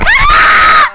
Worms speechbanks
Nooo.wav